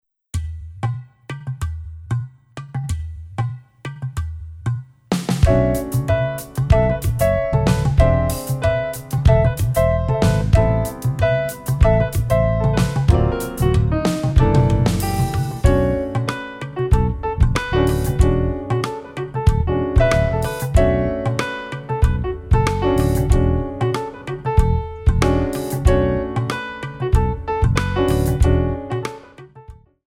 4 bar intro
Caribbean beat
Funky Jazz / Modern